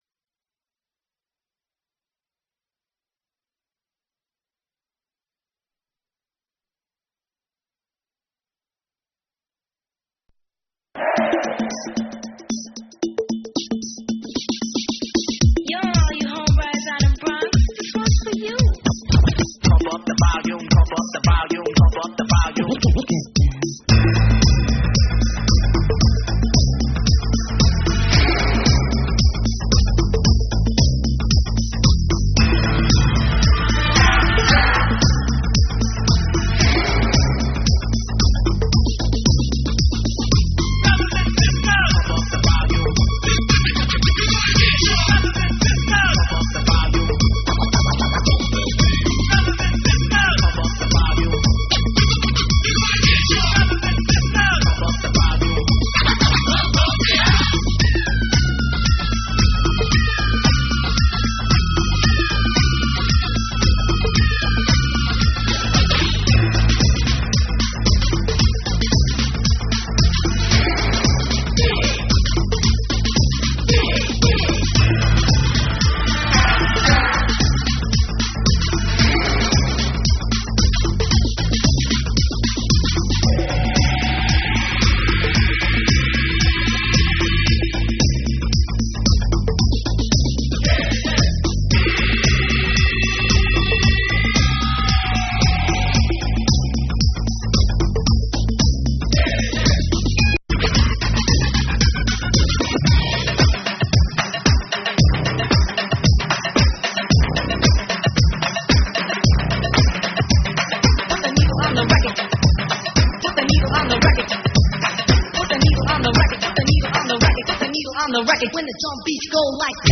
Campbell Co 81, Brossart 39 Thursday, February 27th at Scott High School The complete broadcast replay is presently up and available.